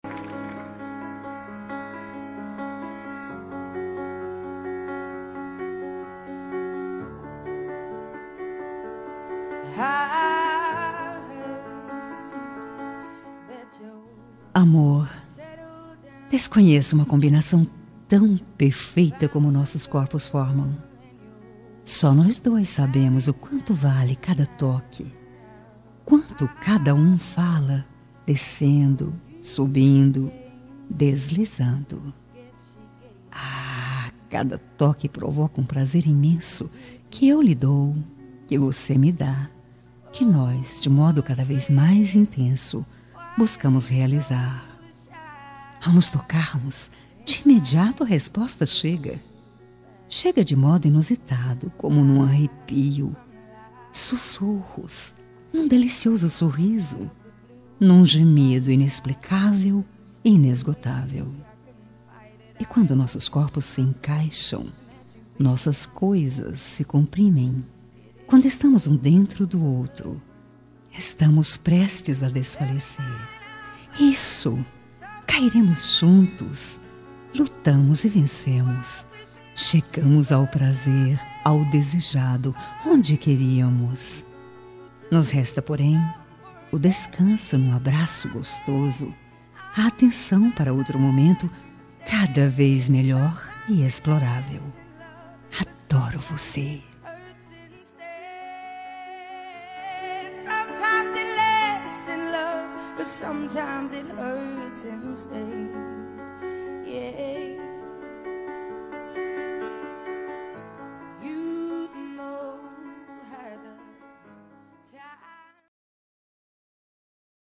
Telemensagem Amante – Voz Feminina – Cód: 5402